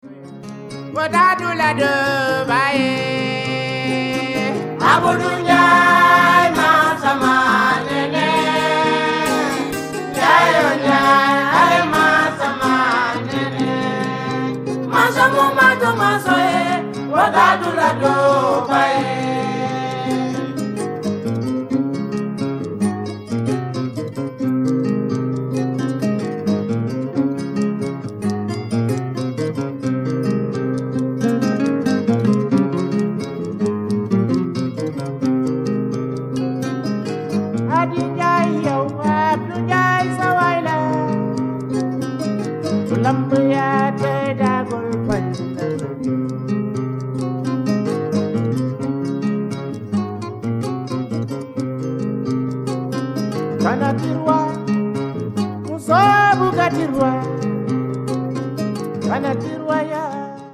Casamance influenced music